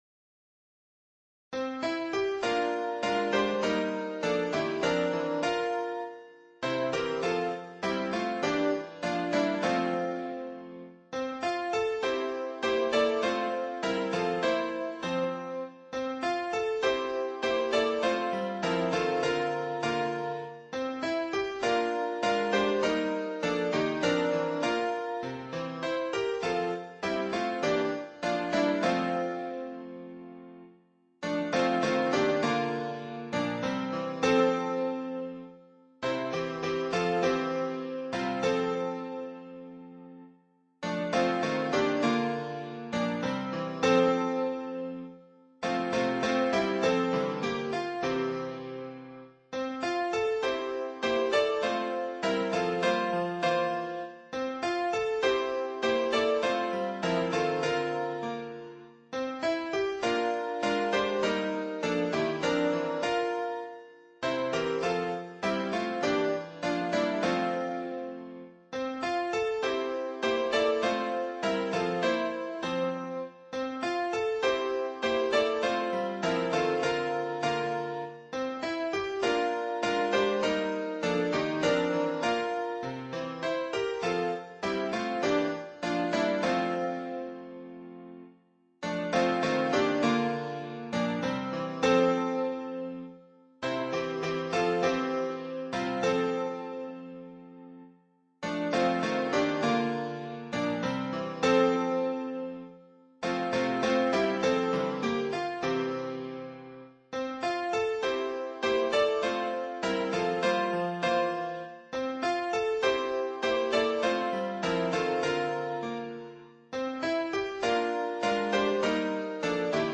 伴奏
原唱
这首歌通过欢快、跳跃、有弹性的旋律来抒发情感。